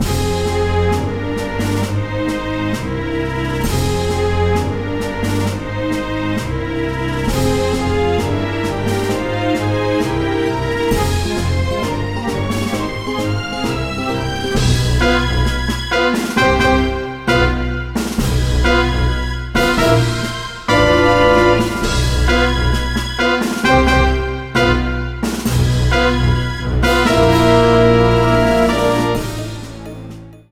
boss battle music
slightly faster variant